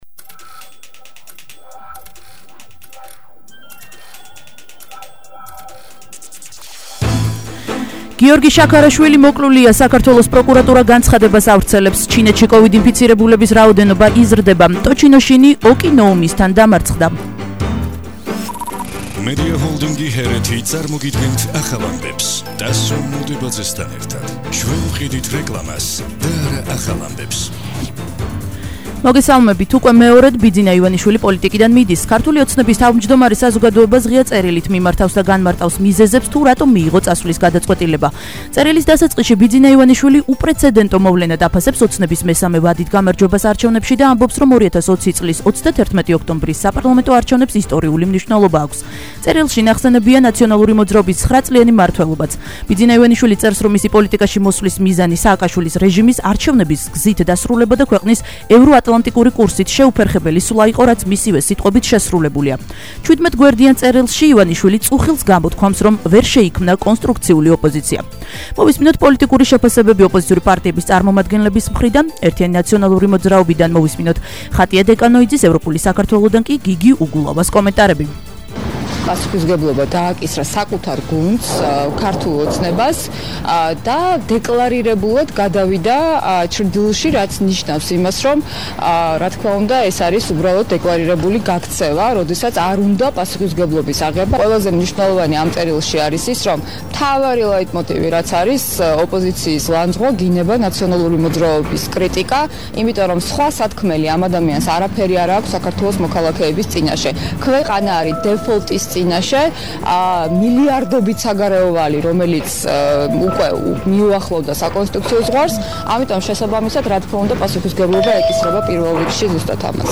ახალი ამბები 13:00 საათზე –11/01/21